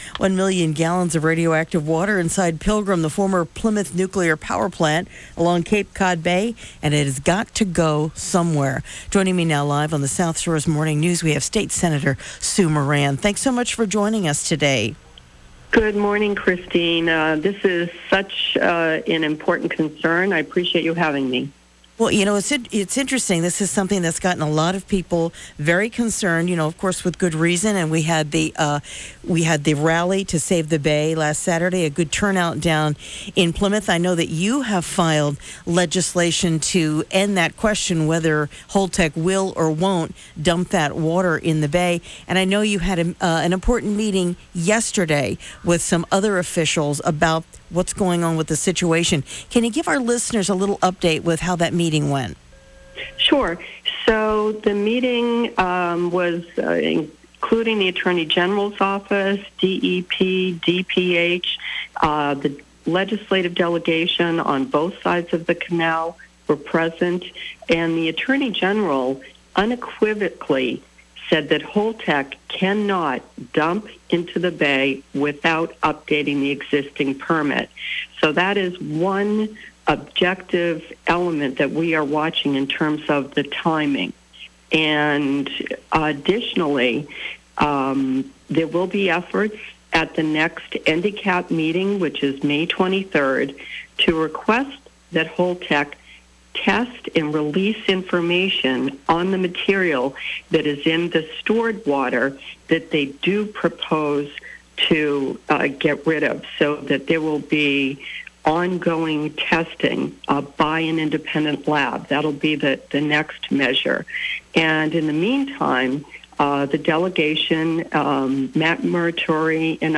State Senator Su Moran Joins WATD to Discuss Legislation Aimed at Preventing Radioactive Water from Being Dumped into Cape Cod Bay